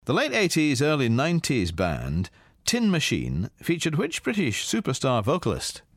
Here's Ken with today's difficult PopMaster question.